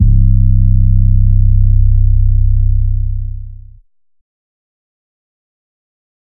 808 (NO BYSTANDERS C).wav